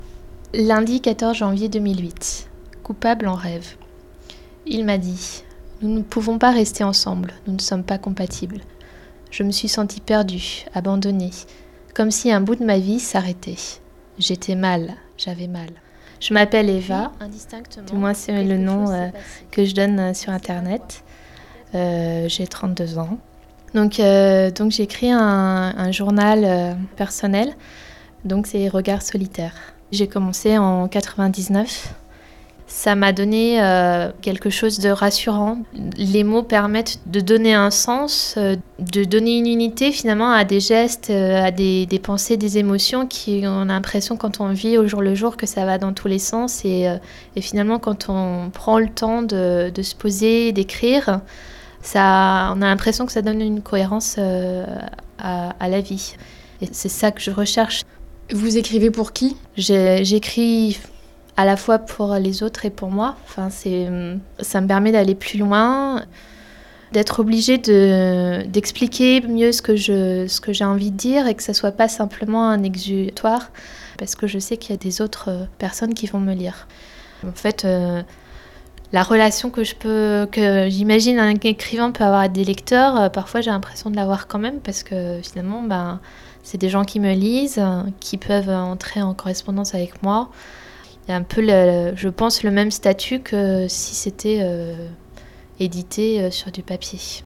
En cliquant ici, vous aurez le MP3 du reportage que ma visiteuse des ondes avait élaboré pour mon passage à l'émission de RFI. Ce reportage, morceaux choisis d'une heure de conversation, a été tronqué à sa diffusion à l'antenne.